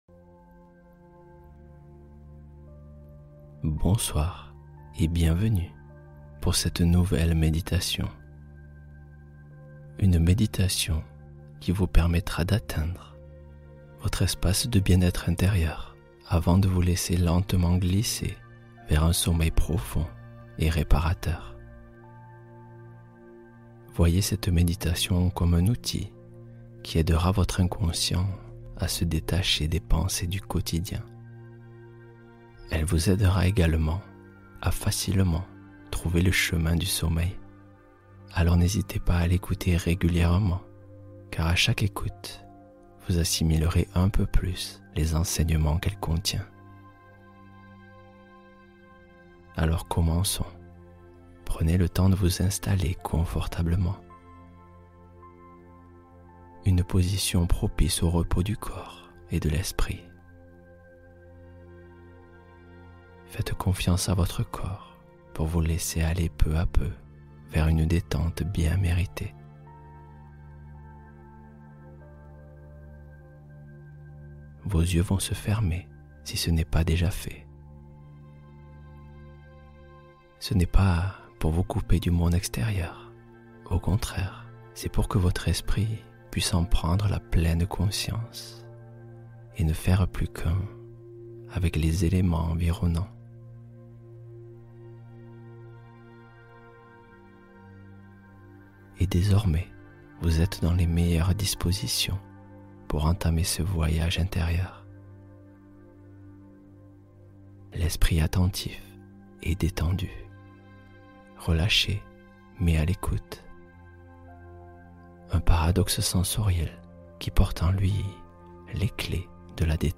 Transformation des Nuits : Méditation du soir pour un sommeil stable